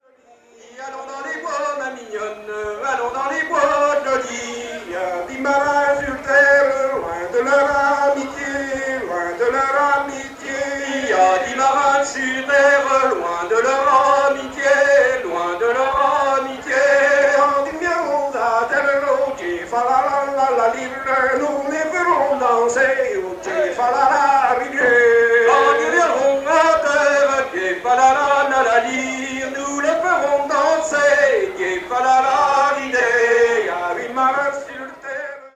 Hanter dro
Entendu au festival des "Assemblées gallèses" en juillet 89